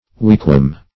Weekwam \Week"wam\, n.